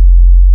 808s
After Dark Sub.wav